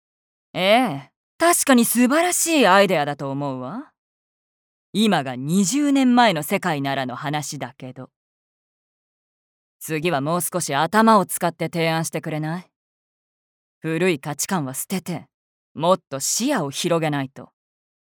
VOICE SAMPLE
セリフ9